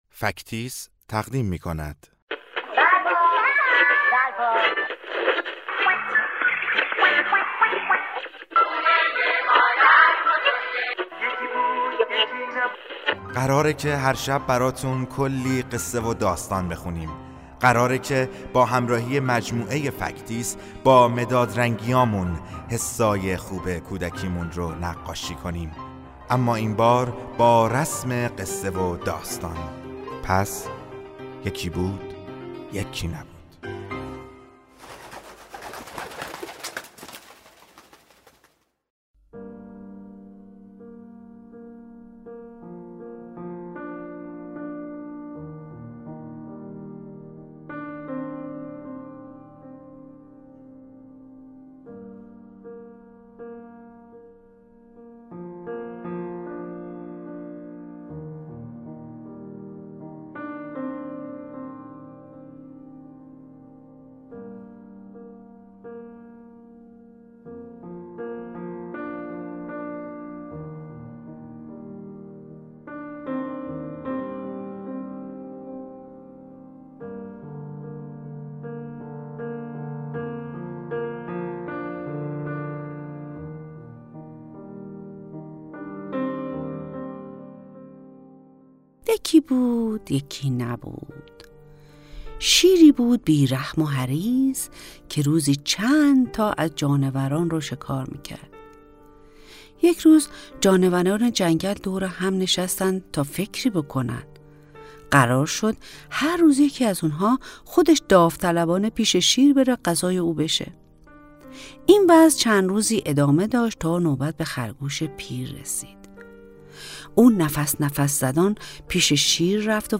قصه کودکانه صوتی شیر و خرگوش پیر